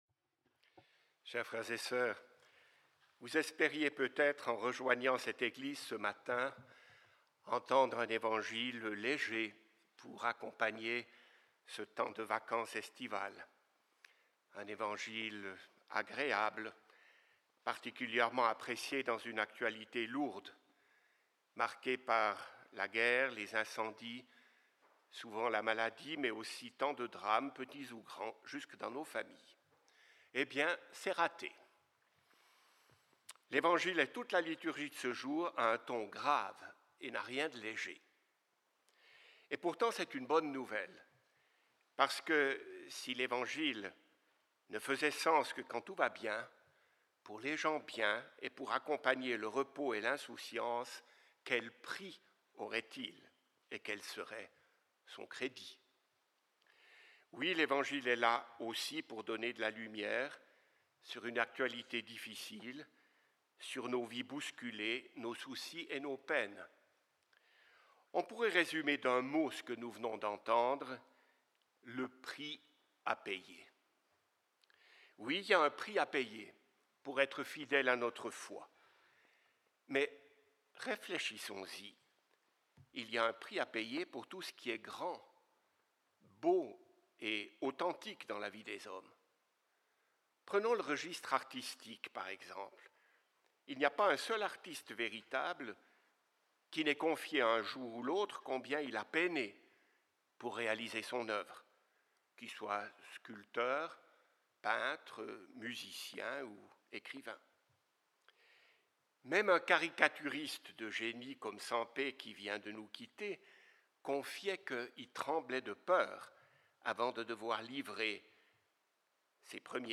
L'homélie